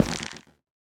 sounds / block / stem / step1.ogg